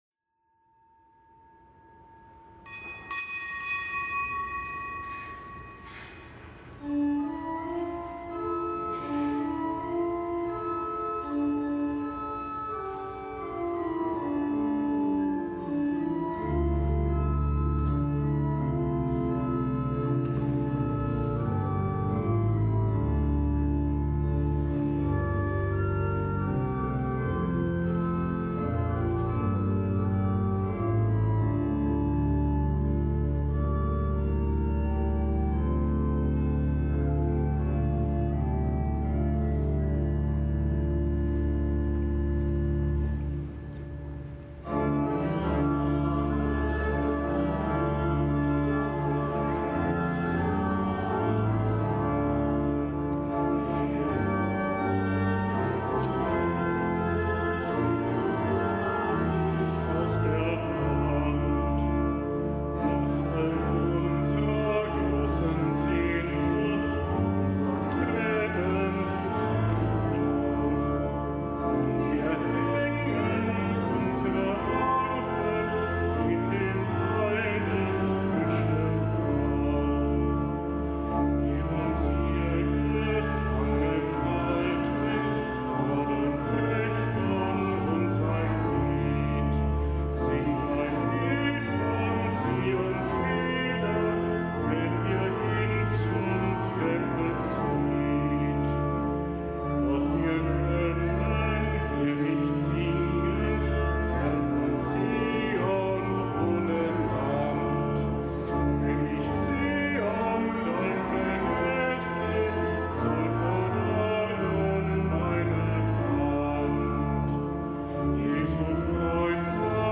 Kapitelsmesse am Donnerstag der vierten Fastenwoche
Kapitelsmesse aus dem Kölner Dom am Donnerstag der vierten Fastenwoche